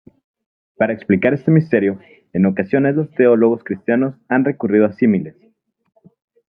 mis‧te‧rio
/misˈteɾjo/